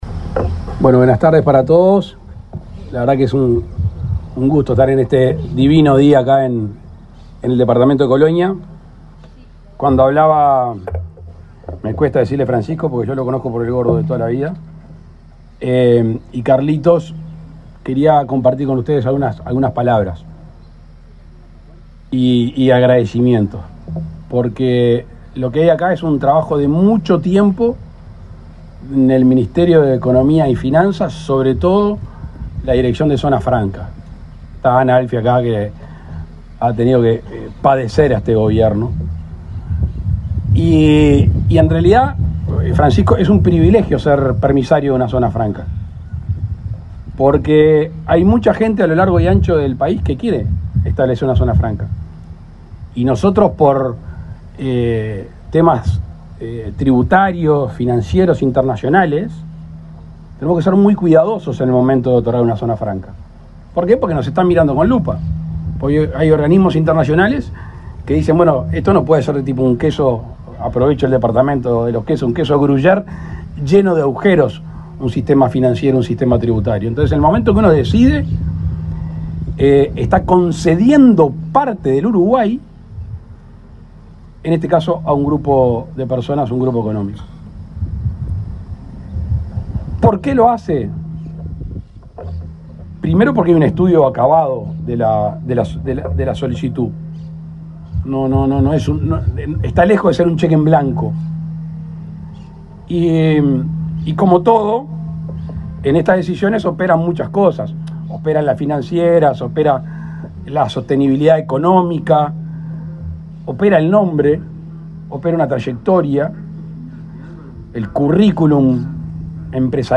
Palabras del presidente Luis Lacalle Pou
Este miércoles 16, el presidente de la República, Luis Lacalle Pou, participó en la ceremonia de inicio de obras en la Zona Franca del Plata, en